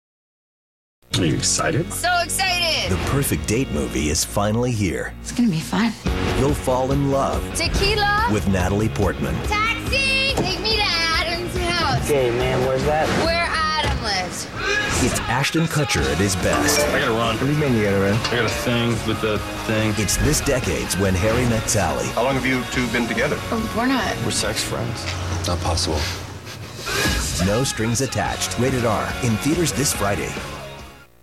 No Strings Attached TV Spots